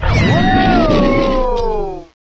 sovereignx/sound/direct_sound_samples/cries/archaludon.aif at master